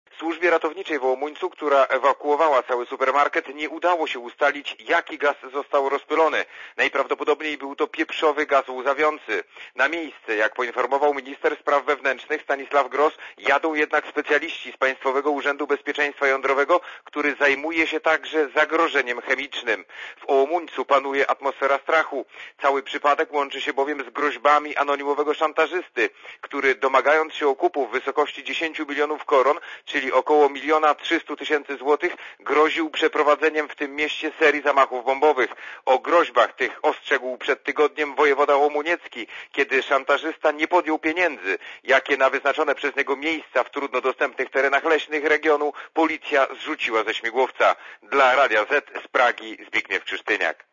Korespondencja z Czech